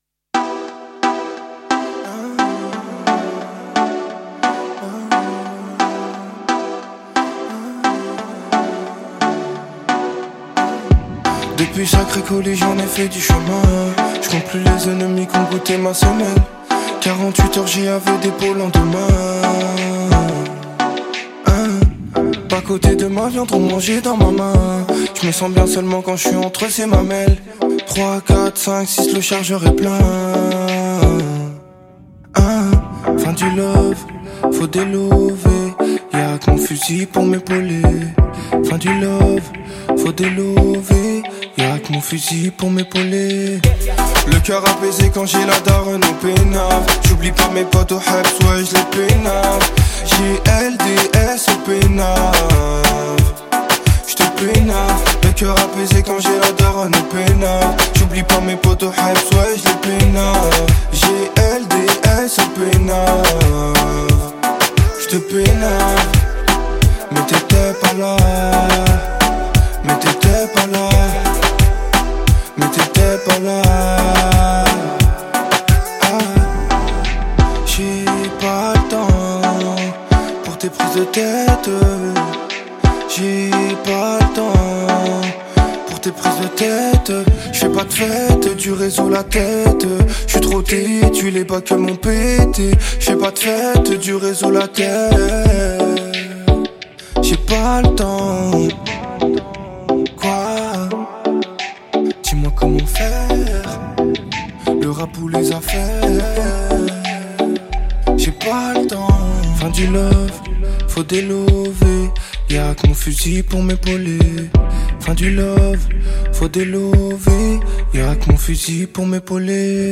39/100 Genres : french rap, pop urbaine Télécharger